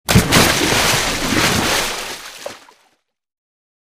Звуки всплеска воды
На этой странице собраны разнообразные звуки всплеска воды: от легкого плеска капель до мощных ударов о поверхность.